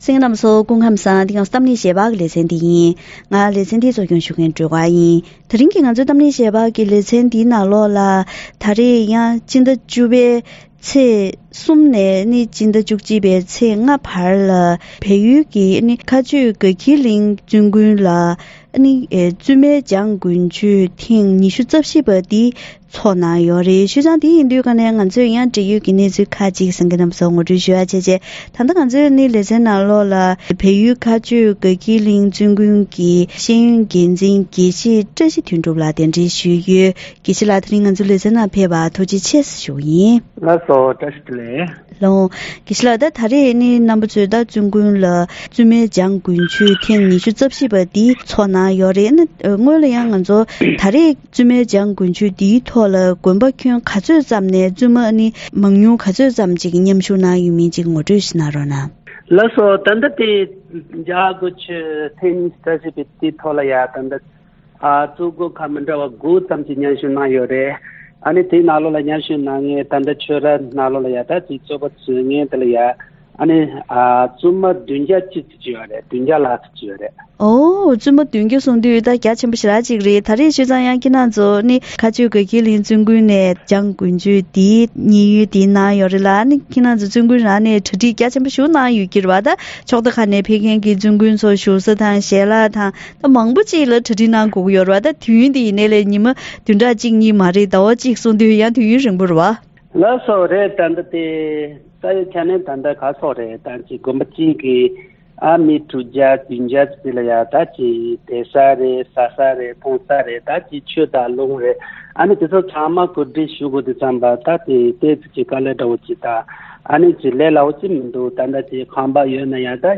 བཙུན་དགོན་ཁག་ཅིག་ནས་ཕེབས་པའི་བཙུན་མ་དང་བལ་ཡུལ་མཁའ་སྤྱོད་དགའ་འཁྱིལ་གླིང་བཙུན་དགོན་གྱི་གཞུང་ཆེན་སློབ་གཉེར་སློབ་གཉེར་སྐོར་ལ་འགྲེལ་ཡོད་དང་བཀའ་མོལ་ཞུས་པ་ཞིག་གསན་རོགས་གནང་།